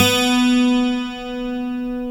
Index of /90_sSampleCDs/Roland L-CD701/GTR_Steel String/GTR_18 String
GTR 12 STR0N.wav